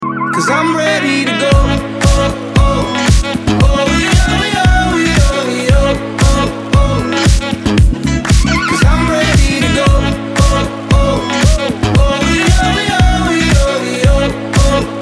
• Качество: 256, Stereo
мужской вокал
веселые
Веселый мотивчик!